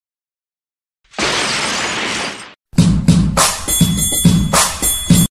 chandelier boom